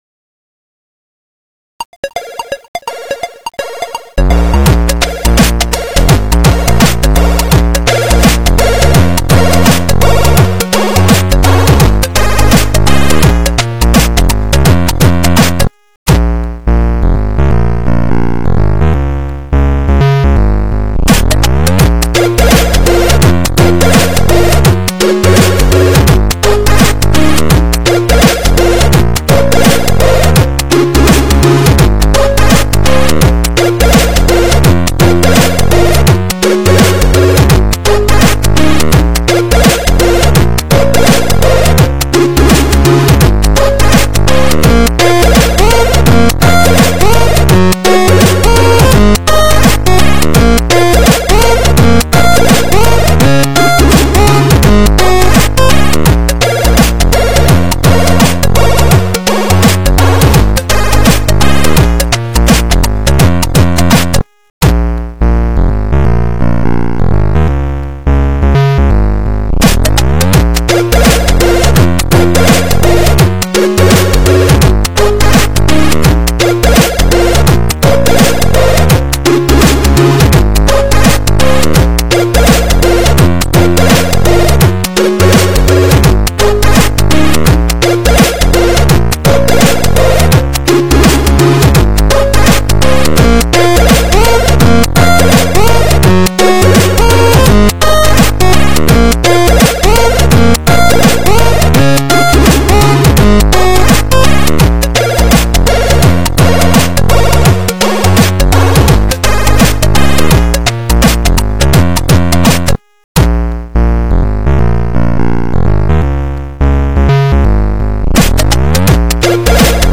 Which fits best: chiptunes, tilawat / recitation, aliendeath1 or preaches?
chiptunes